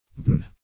Search Result for " baigne" : The Collaborative International Dictionary of English v.0.48: Baigne \Baigne\ (b[=a]n), v. t. [F. baigner to bathe, fr. L. balneum bath.] To soak or drench.